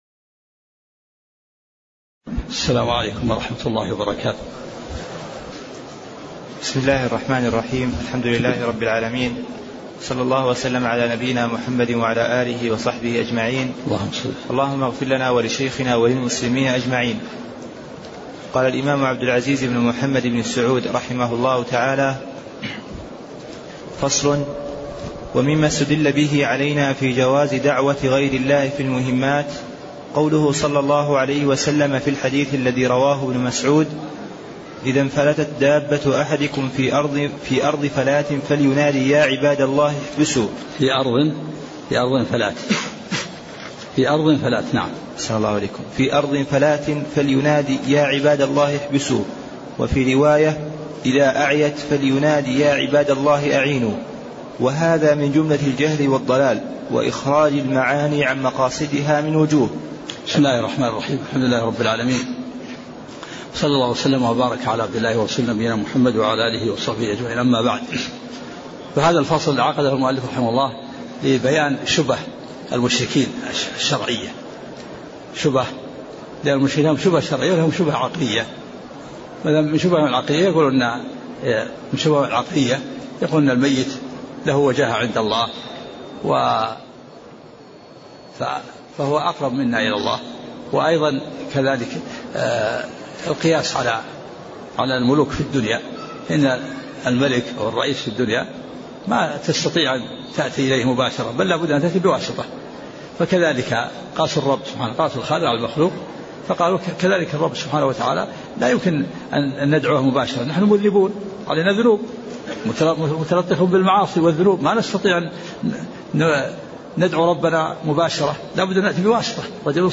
تاريخ النشر ٩ شعبان ١٤٣٤ هـ المكان: المسجد النبوي الشيخ: عبدالعزيز الراجحي عبدالعزيز الراجحي من قوله: ومّما إستدّل به علينا في جواز دعوة غير الله في المهمات (05)